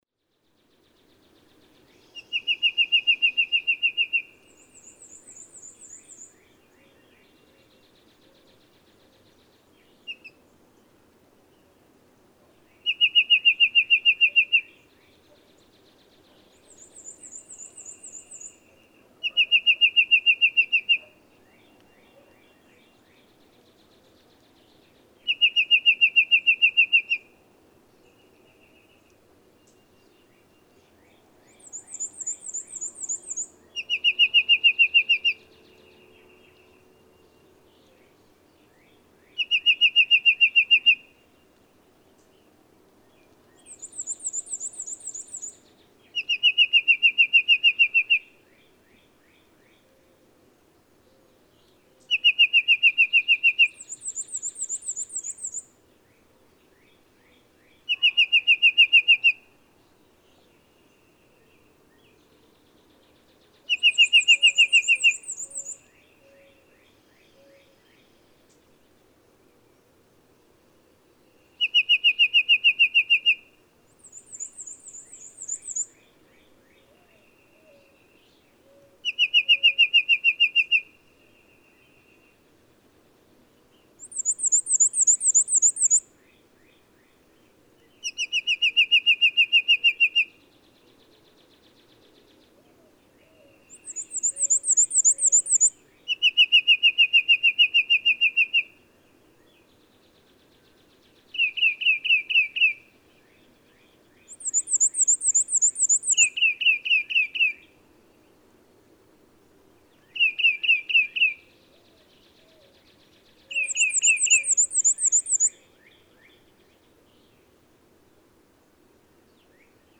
Black-crested titmouse
The singing behavior of these two birds seems indistinguishable: Songs are similar, both have smallish song repertoires and sing with "eventual variety," and neighboring males match each other's songs.
Sound like a tufted titmouse? I think so, but it's not. Hear the matched countersinging, with a bird in the background singing the same song as the near bird?
At 1:40 the foreground titmouse does what you'd expect a tufted to do, switch to a new song; listen closely, and you'll hear the background bird also singing this new song (perhaps most evident at 3:08 and 5:10). Enjoy also the black-and-white warbler singing in a much higher register.
April 12, 2013. Kerr Wildlife Management Area, Hunt, Texas. (5:41)
694_Black-crested_Titmouse.mp3